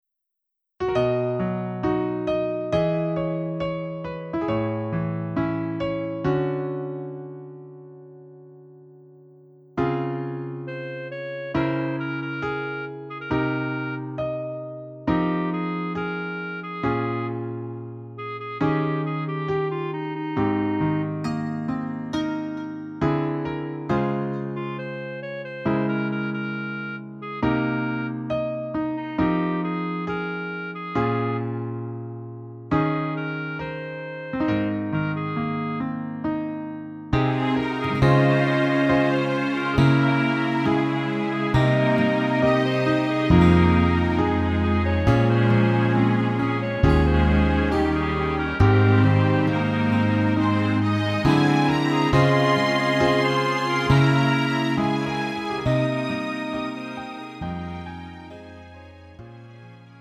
음정 -1키 4:05
장르 가요 구분 Lite MR